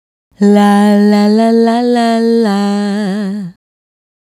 La La La 110-G#.wav